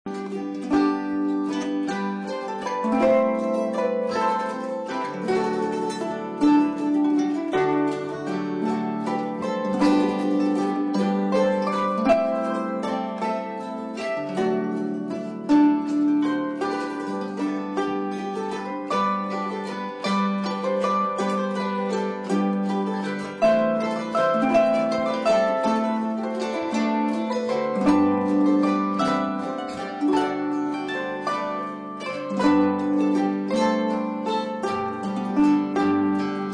Harp
Mandolin
Guitar